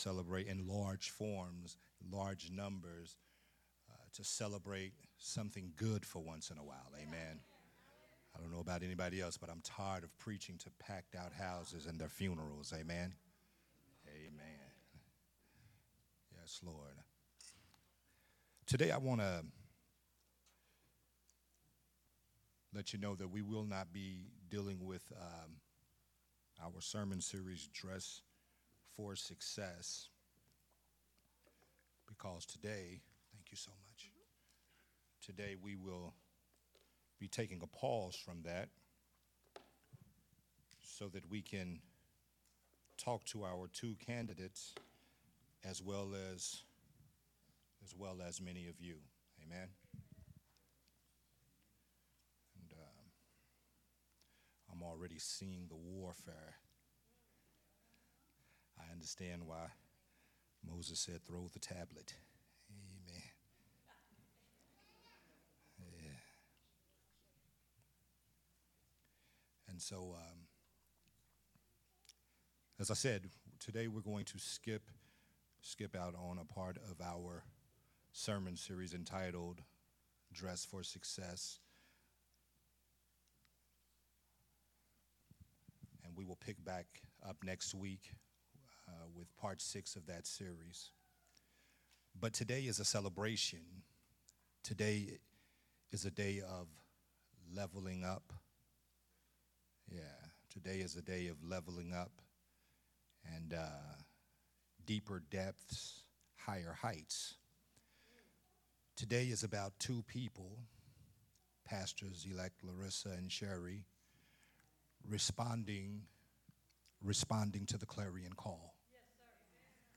Ordination Service
Sunday Morning Worship Service